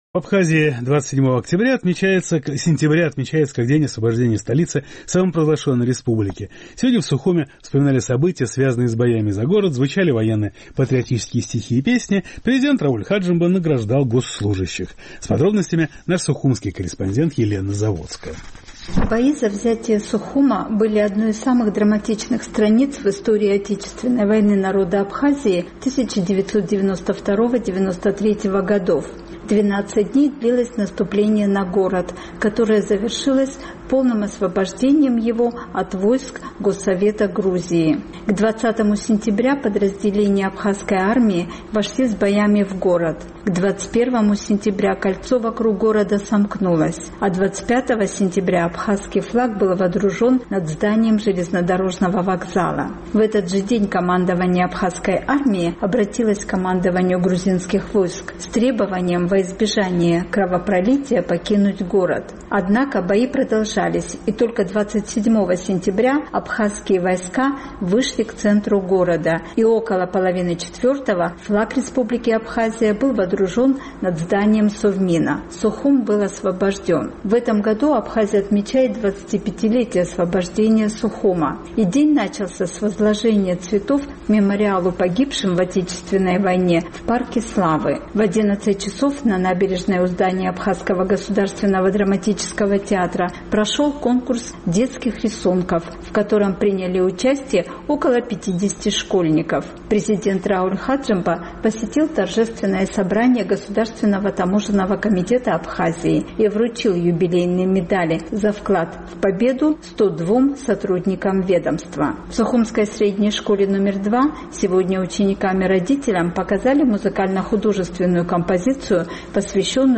27 сентября в Абхазии отмечается как день освобождения Сухума. Сегодня здесь вспоминали события, связанные с боями за город, звучали военно-патриотические стихи и песни, дети рисовали на асфальте, президент награждал госслужащих.